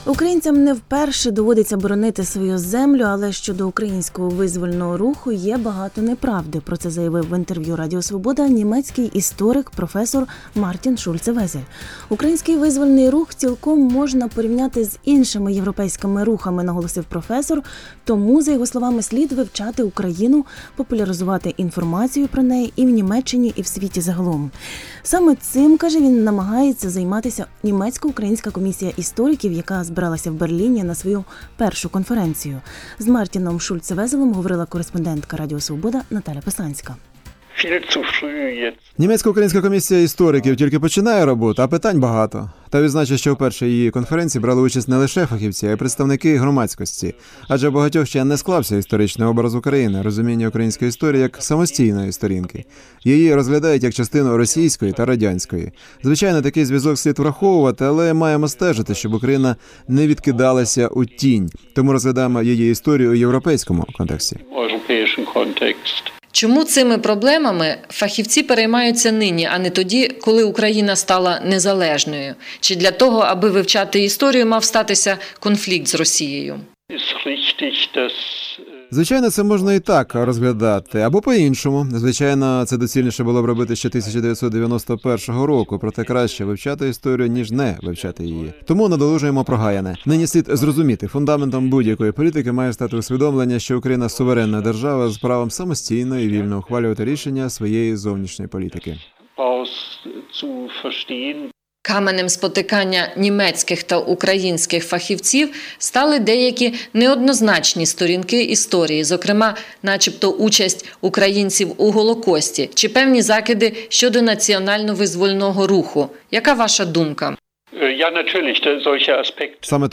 Про це заявив в інтерв’ю Радіо Свобода німецький історик